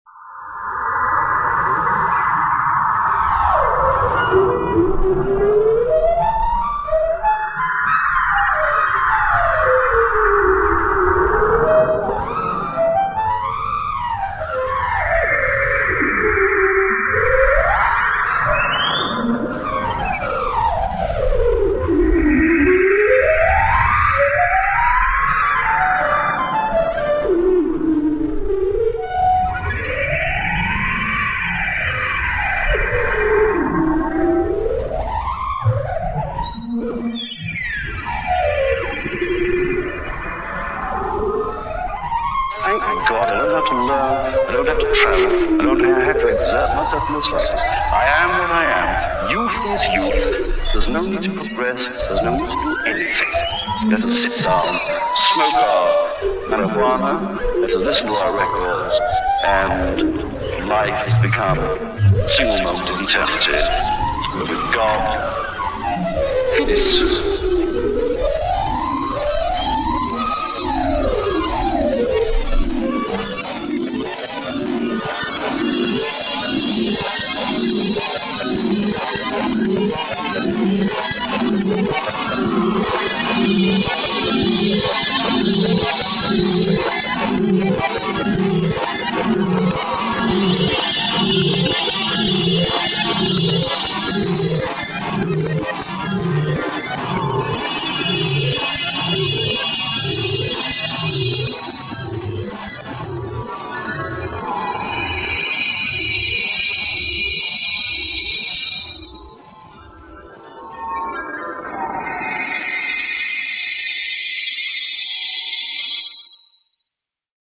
This is just noise.
It really is just noise.